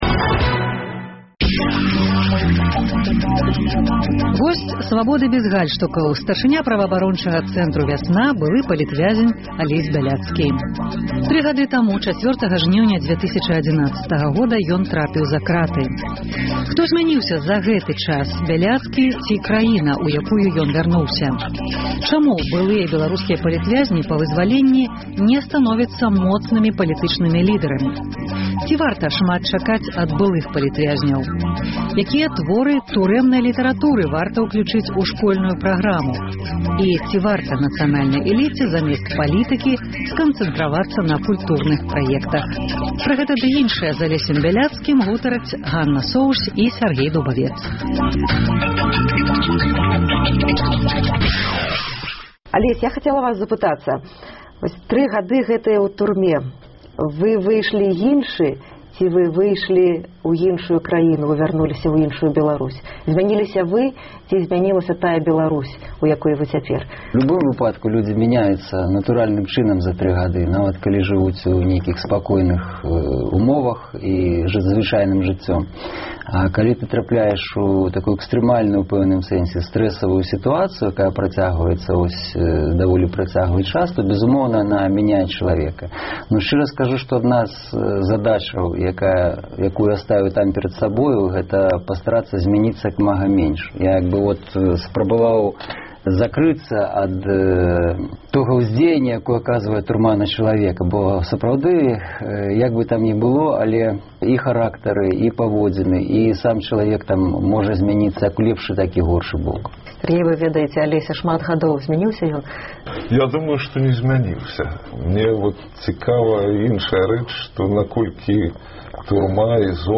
Госьць «Свабоды бяз гальштукаў» — старшыня праваабарончага цэнтру «Вясна», былы палітвязень Алесь Бяляцкі.